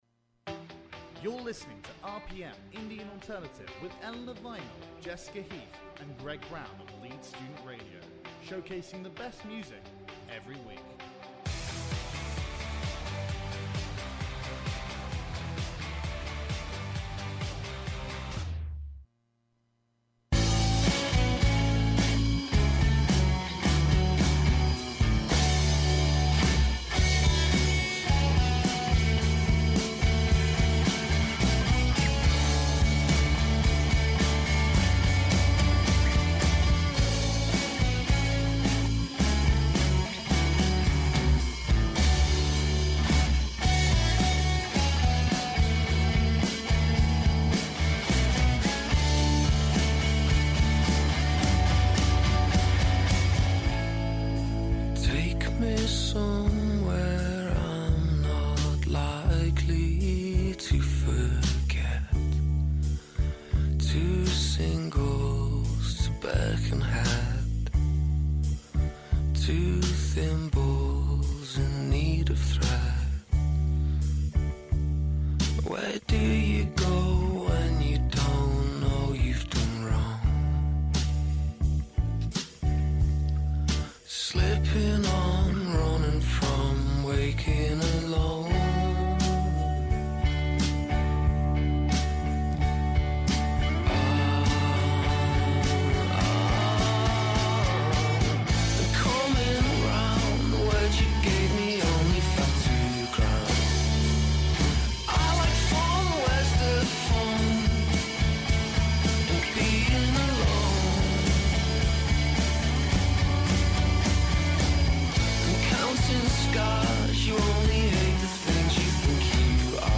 RPM Indie and Alternative 21/11/15